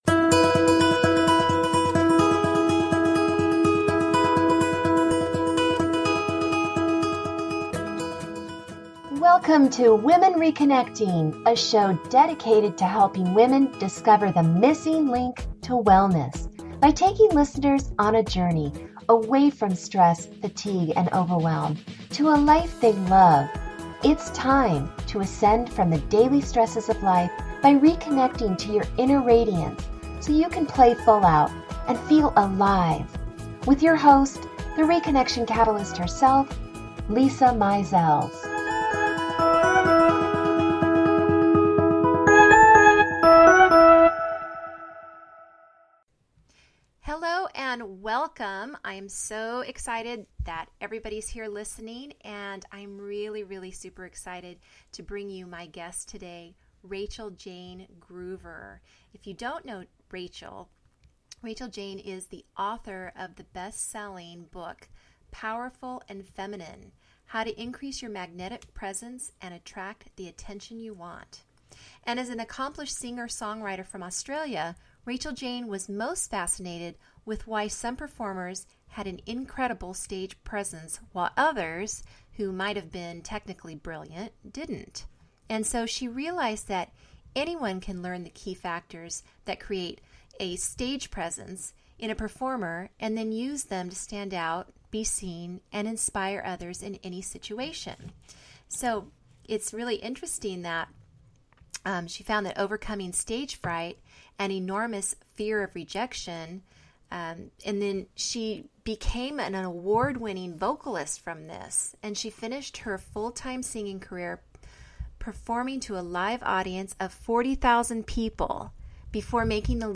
The guests I'll be interviewing on this show will give you strategies and tips that will open your eyes to new ways of thinking about life.